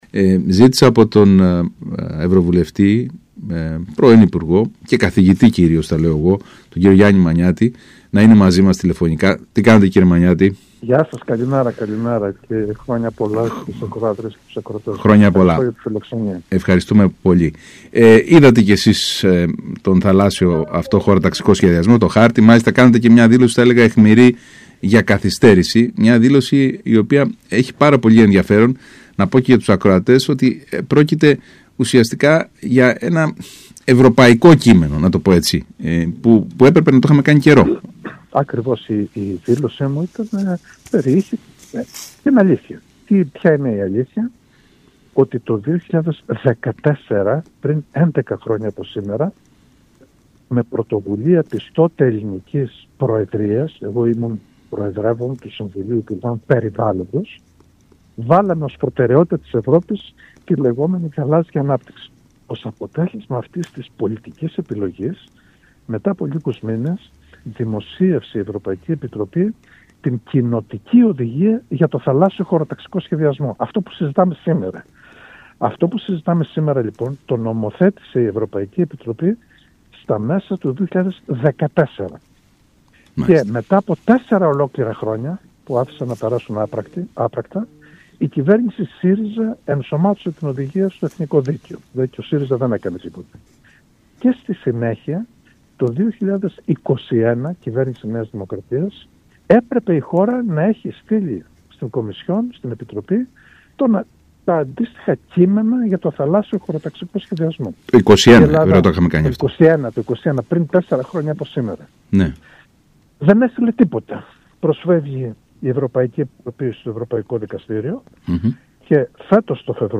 μιλώντας στον ΣΚΑΙ Κρήτης